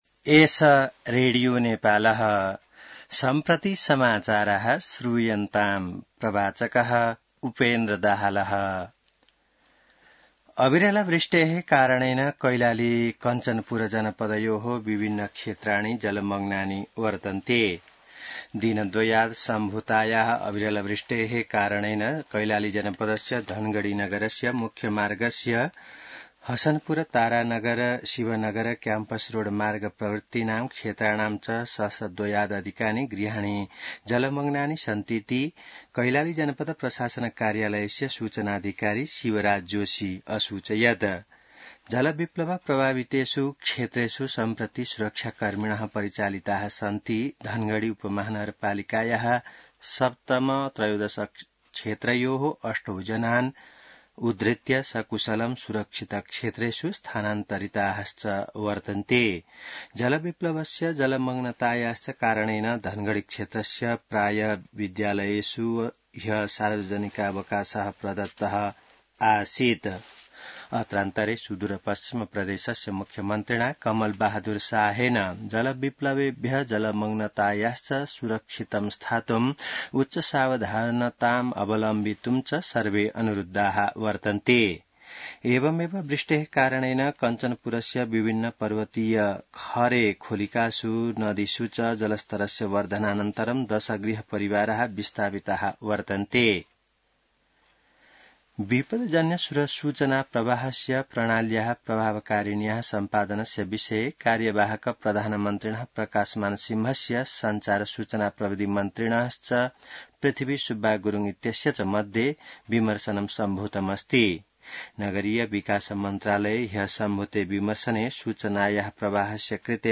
An online outlet of Nepal's national radio broadcaster
संस्कृत समाचार : १७ असार , २०८२